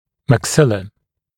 [mæk’sɪlə][мэк’силэ]верхняя челюсть (позвоночных животных)